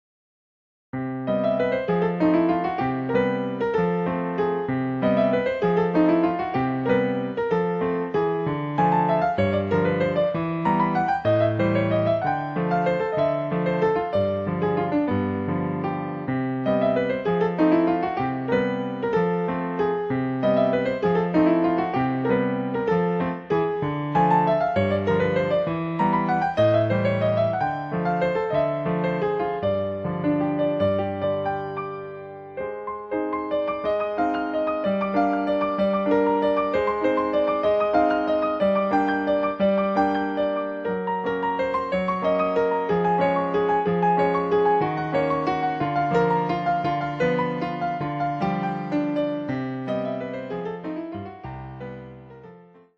〜 透明感あふれる3rdピアノソロアルバム。
3rdピアノソロアルバム。